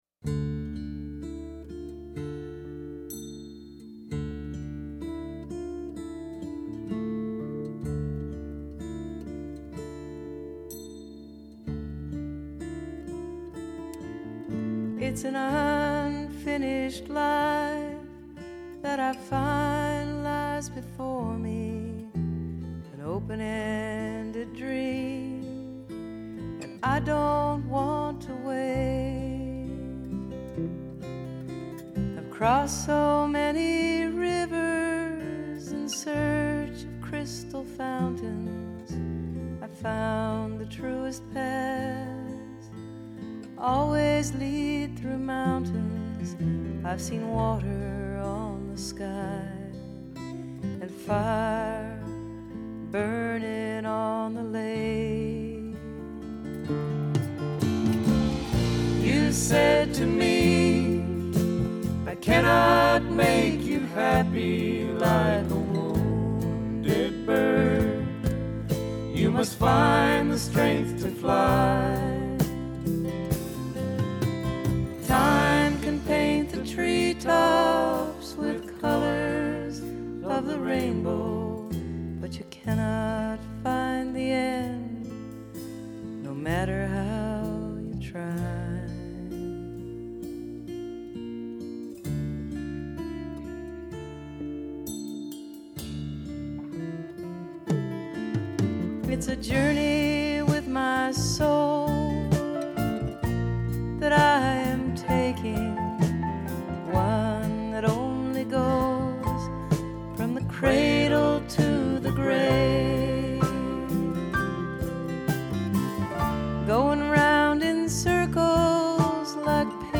Originally in Dm, capo 5.